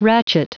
Prononciation du mot ratchet en anglais (fichier audio)
Prononciation du mot : ratchet